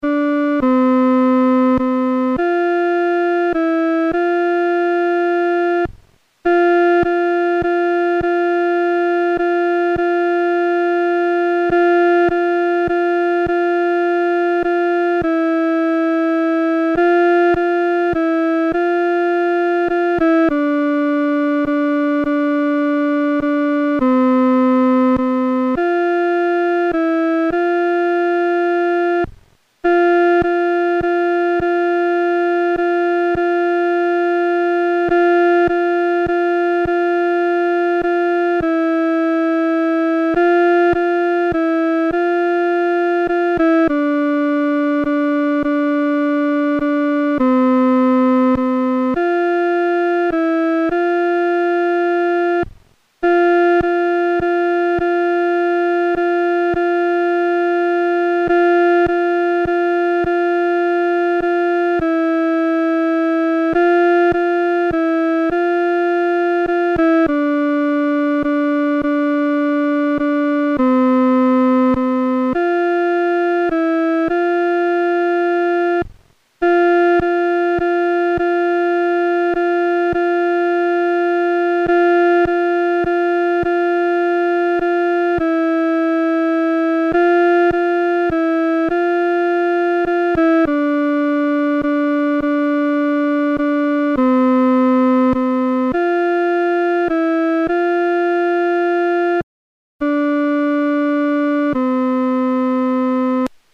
伴奏
女低